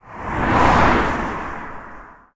car3.ogg